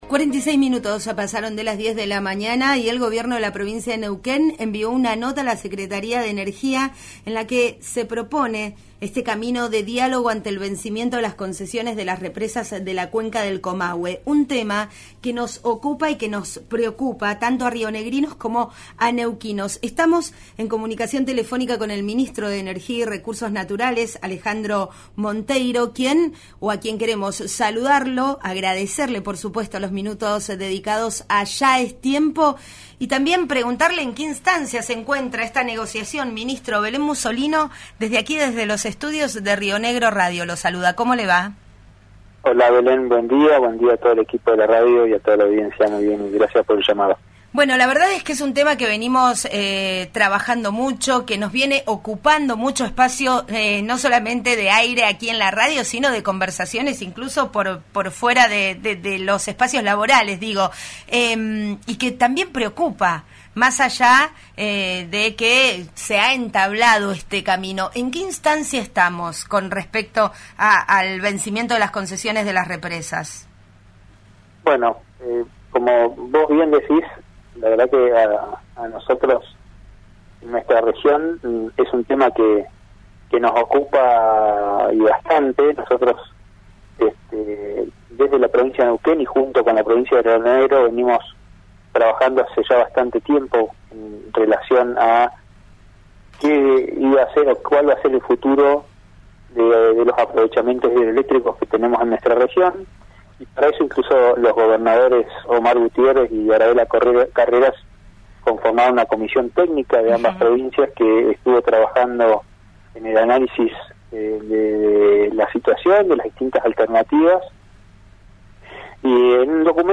«Tuve la oportunidad de estar con la secretaría de Energía aquí en Tratayén (Vaca Muerta), que vino a recorrer la obra de gasoducto (Néstor Kirchner) y le volvimos a reclamar la necesidad de una convocatoria en el menor tiempo posible para poder llevar a definiciones concretas considerando la postura de las provincias», dijo en diálogo con Ya es Tiempo en RÍO NEGRO RADIO.
Escuchá a Alejandro Monteiro en RÍO NEGRO RADIO: